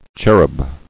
[cher·ub]